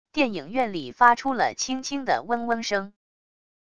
电影院里发出了轻轻的嗡嗡声wav音频